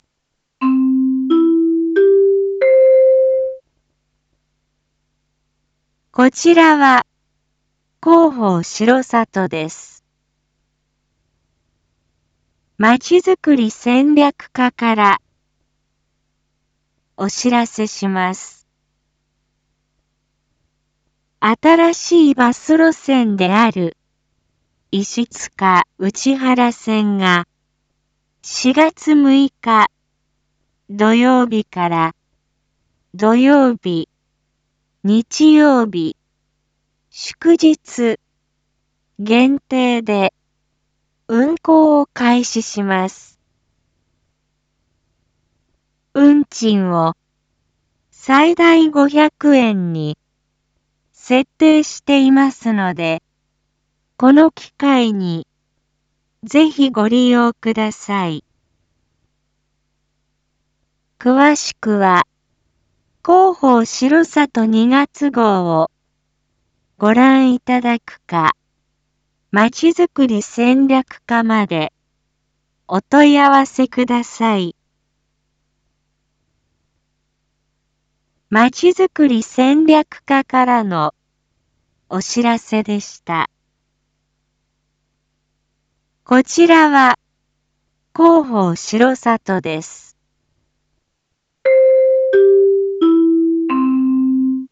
一般放送情報
Back Home 一般放送情報 音声放送 再生 一般放送情報 登録日時：2024-04-05 19:01:36 タイトル：石塚・内原線の運行開始について① インフォメーション：こちらは広報しろさとです。